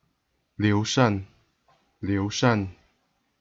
Standard Mandarin
Hanyu PinyinLíu Shàn
pronunciation, 207–271),[1][a] courtesy name Gongsi, was the second and last emperor of the state of Shu Han during the Three Kingdoms period.